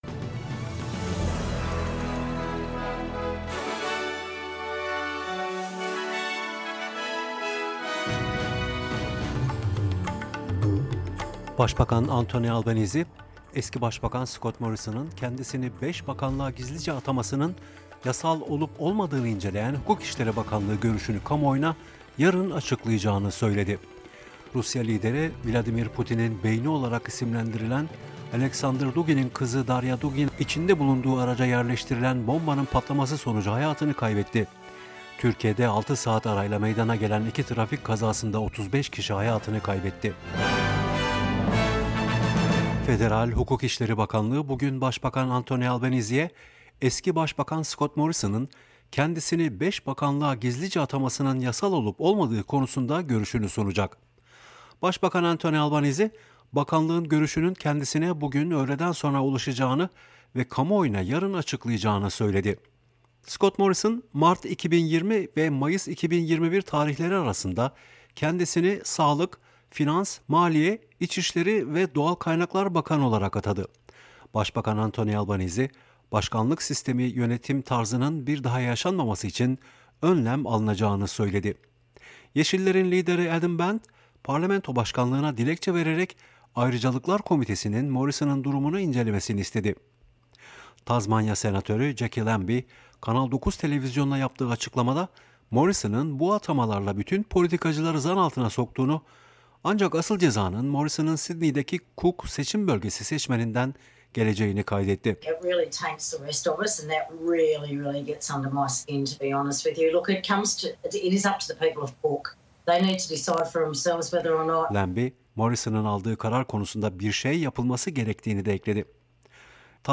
SBS Türkçe Haberler 22 Ağustos